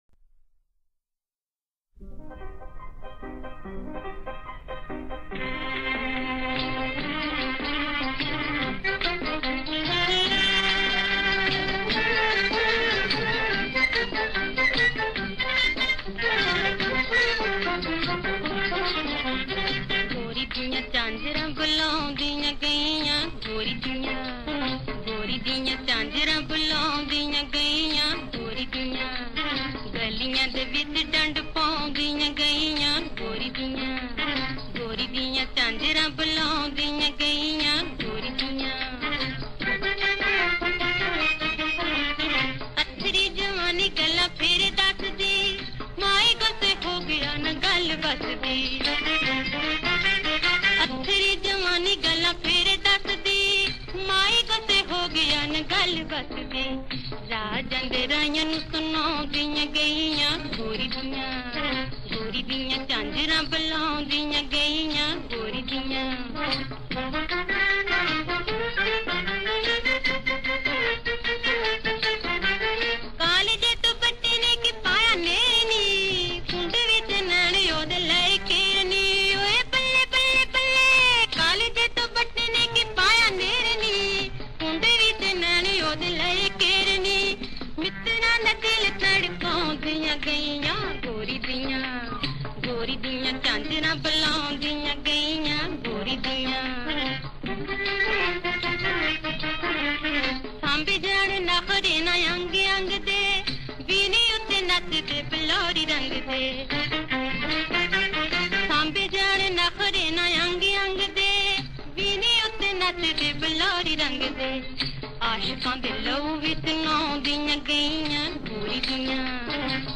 Kalaam/Poetry , Punjabi